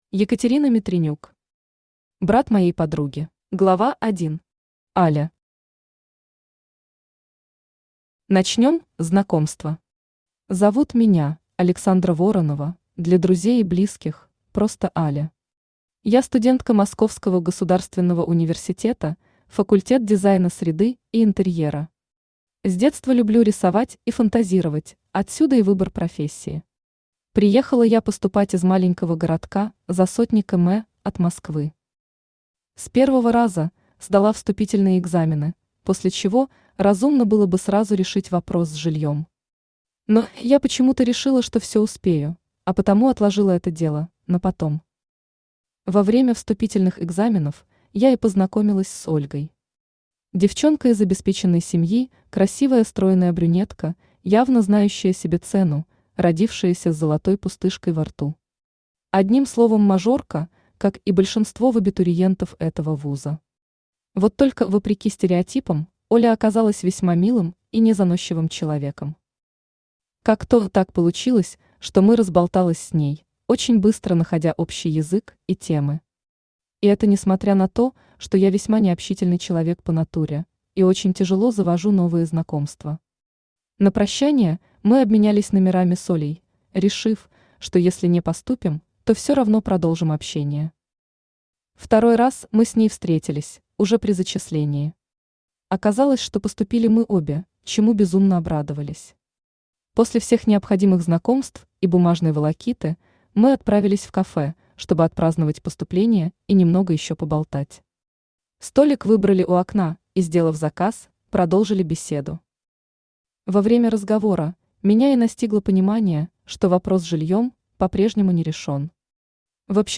Аудиокнига Брат моей подруги | Библиотека аудиокниг
Aудиокнига Брат моей подруги Автор Екатерина Митринюк Читает аудиокнигу Авточтец ЛитРес.